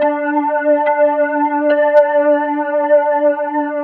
cch_synth_loop_neworder_125_Dm.wav